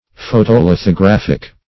Search Result for " photolithographic" : The Collaborative International Dictionary of English v.0.48: Photolithographic \Pho`to*lith`o*graph"ic\, n. Of or pertaining to photolithography; produced by photolithography.
photolithographic.mp3